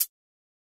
Tm8_HatxPerc19.wav